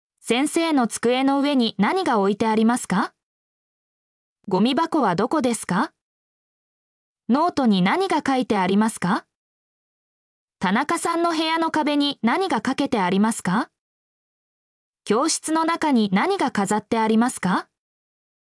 mp3-output-ttsfreedotcom-10_WimQcABL.mp3